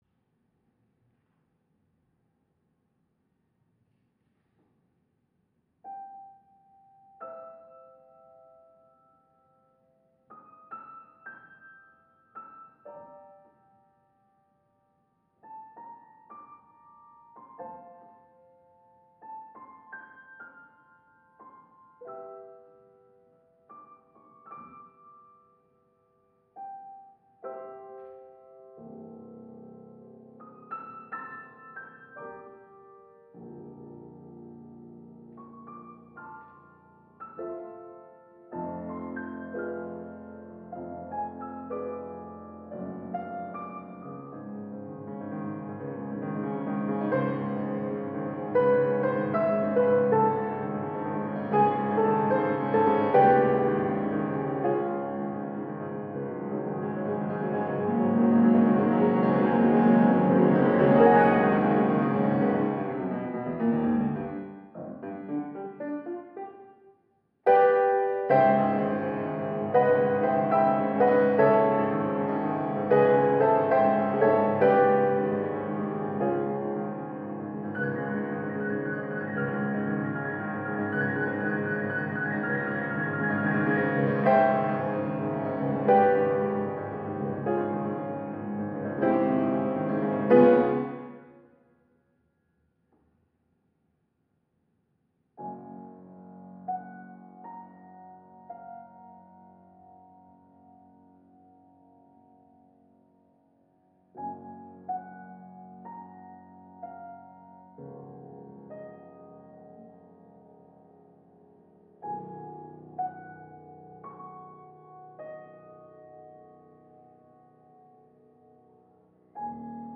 Just a fun piano solo from my first year of college!